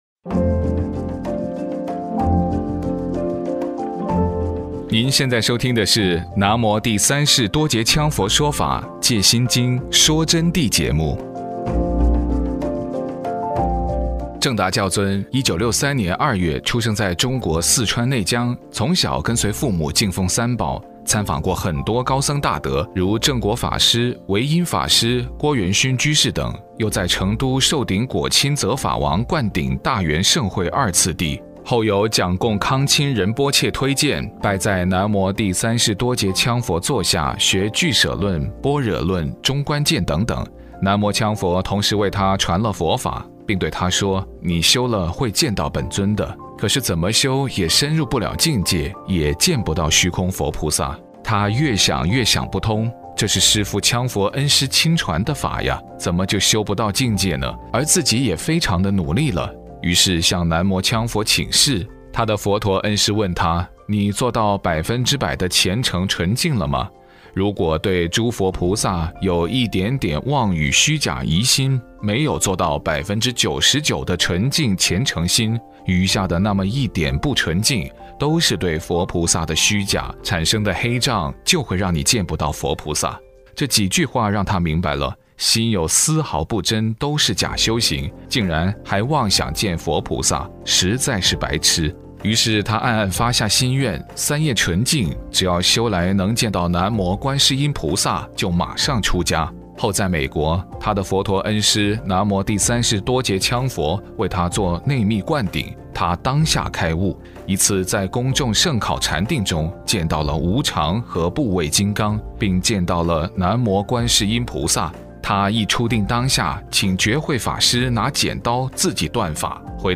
佛弟子访谈（五十四）南无羌佛拿杵上座展显佛陀举世无双的体质体力 – 福慧网